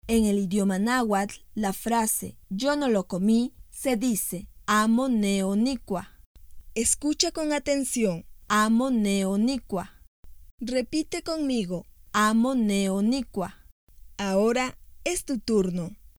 En la lengua náhuatl de la variante de la Sierra de Zongolica, los sabores tienen nombre y escritura, te invitamos a conocerlos y a estudiarlos, para lograr su correcta pronunciación.